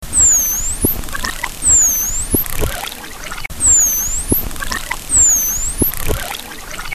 Cliquez sur les spectrogrammes pour écouter les enregistrements sonores sous-marins de grands dauphins collectés sur la côte ouest du Cotentin :
SIFFLEMENTS
sifflements-livre.mp3